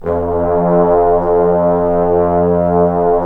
Index of /90_sSampleCDs/Roland LCDP06 Brass Sections/BRS_Bs.Trombones/BRS_Bs.Bone Sect